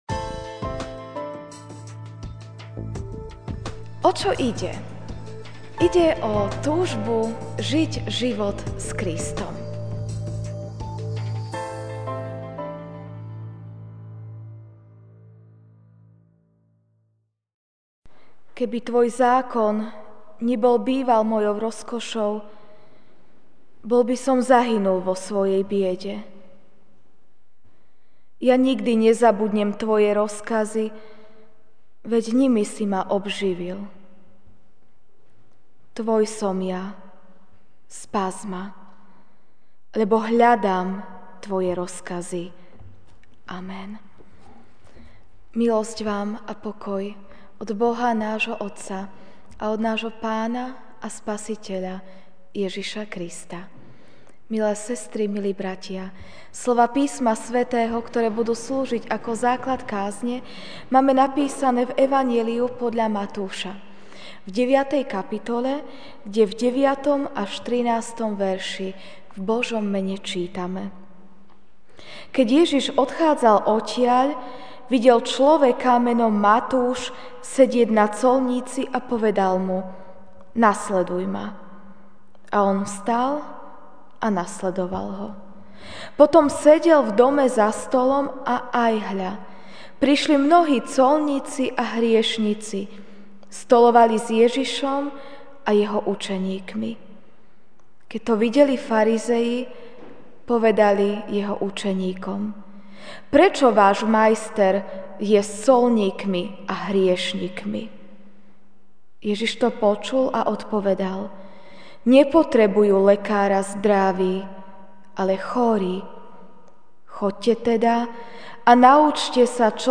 Večerná kázeň: